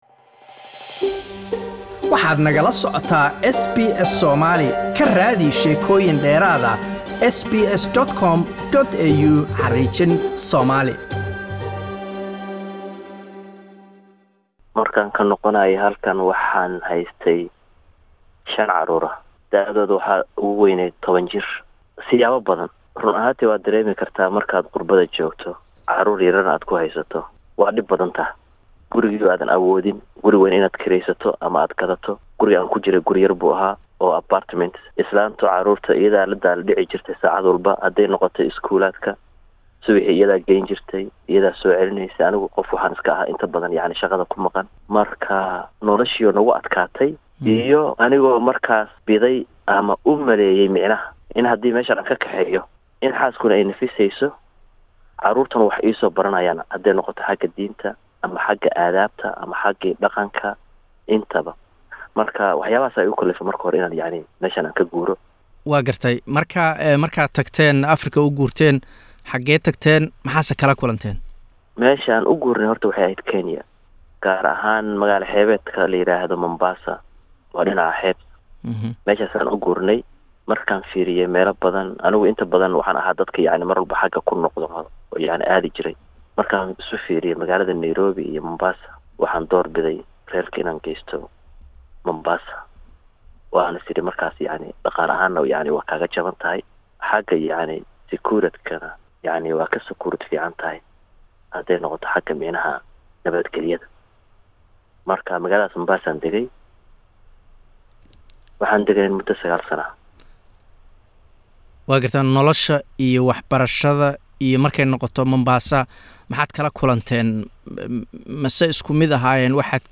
Waalidiin badan ayaa caruurta ka kexeeya dalalka qurbaha iyagoo sheegay inay dhaqanka iyo diinta soo barayaan laakiin inta badan dib ayaa caruurtaas loogu soo celiyaaa dalalkii ay ku dhasheen. Haddaba maxaa sababa in haddba qoysasku meel u guraan? Waxaa nooga waramay Aabbe laftiisu caruurta dejiyay Kenya kadibna Australia ku soo celiyay.